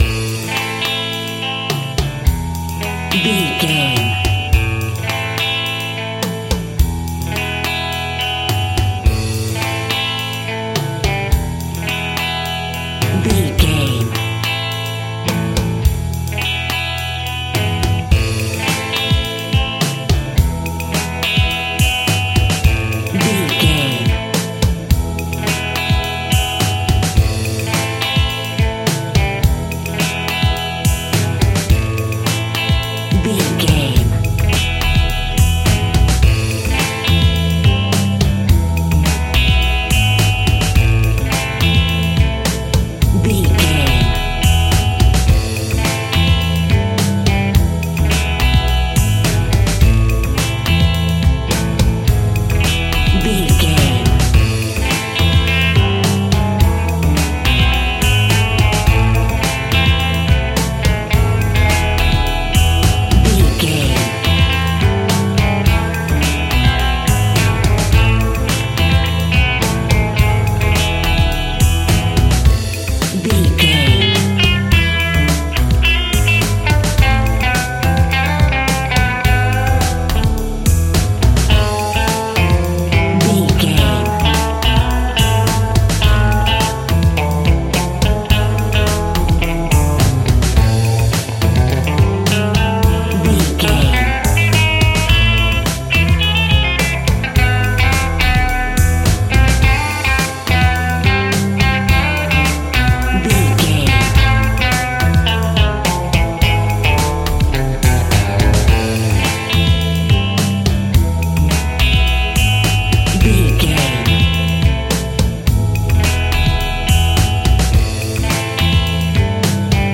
Exotic and world music!
Aeolian/Minor
World Music
strings
brass
percussion